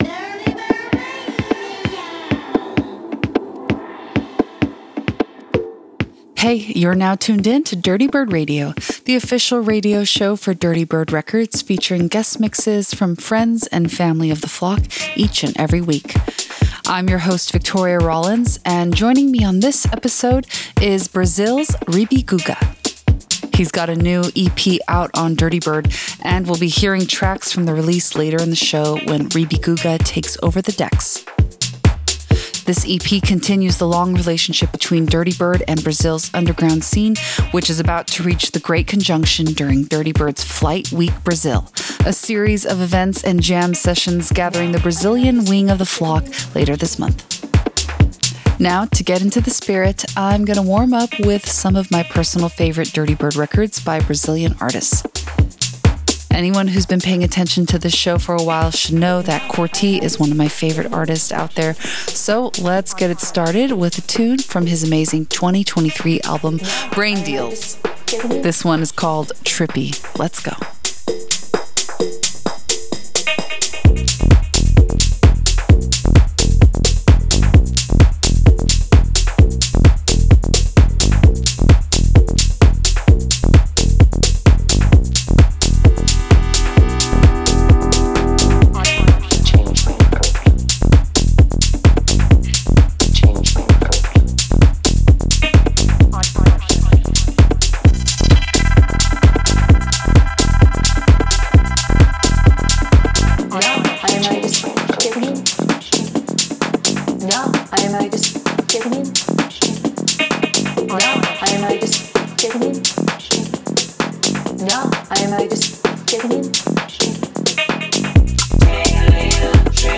VR warm-up set: